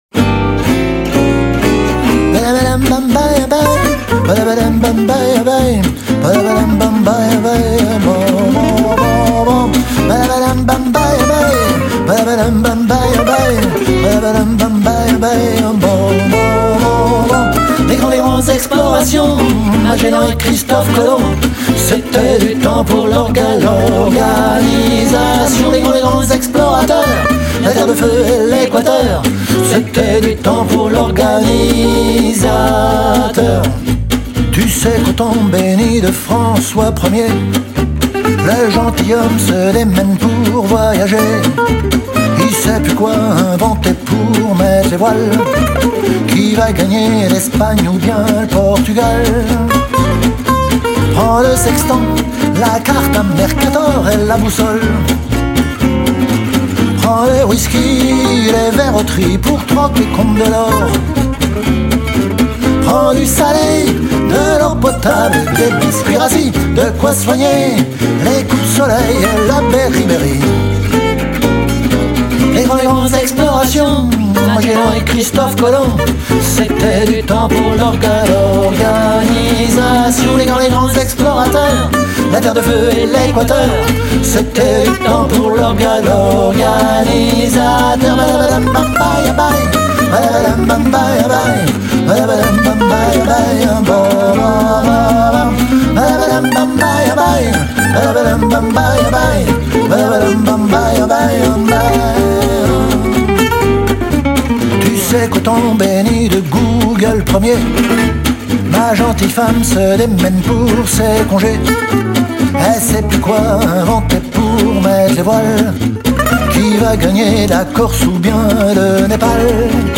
Si vous aimez le swing
les guitares